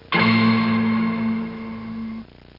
Ding Inst Sound Effect
ding-inst.mp3